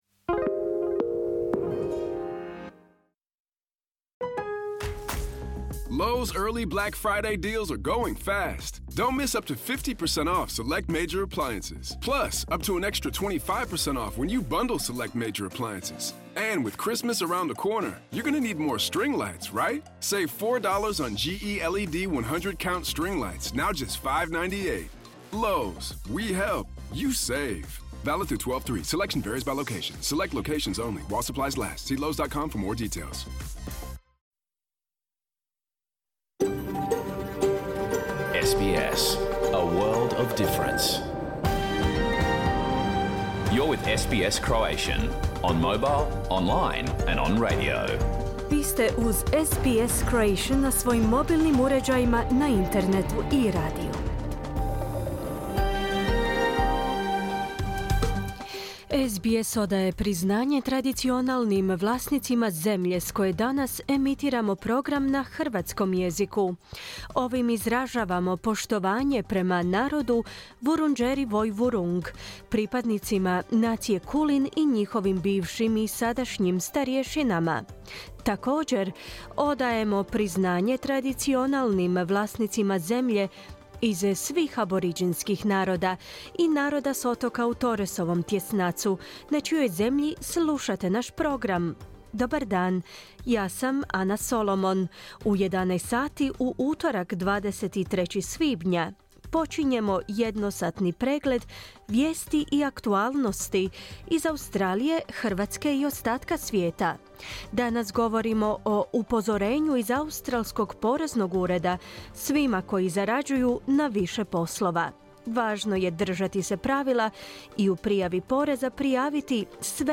Pregled vijesti i aktualnih tema iz Australije, Hrvatske i ostatka svijeta. Program je emitiran uživo u utorak, 23. svibnja u 11 sati.